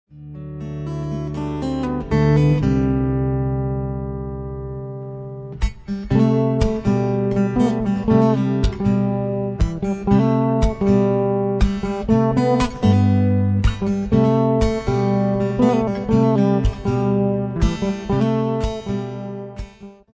chitarra acustica
sitar